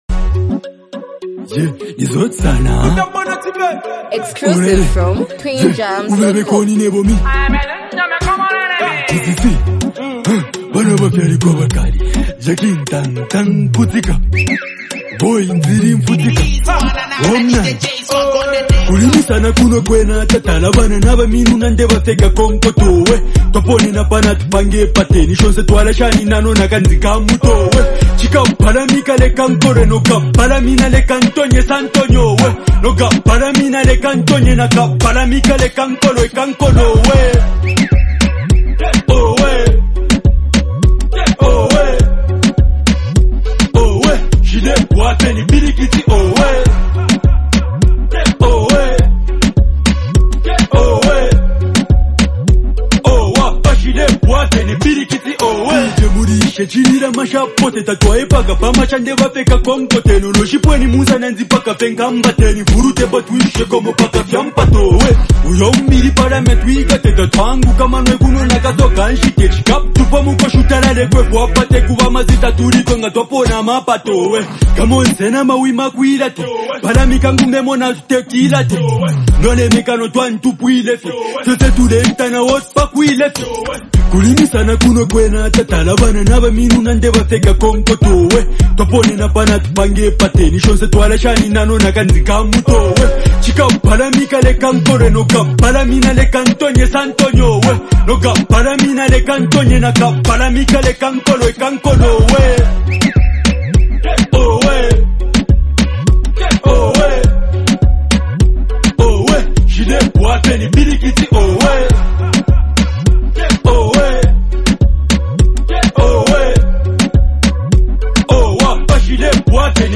emotionally charged song
bringing a soulful and vulnerable perspective